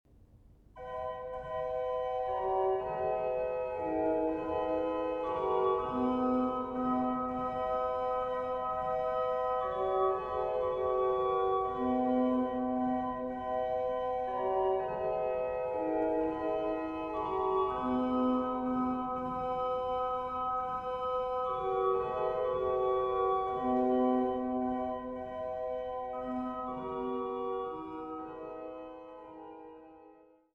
an den Freiberger Silbermann-Orgeln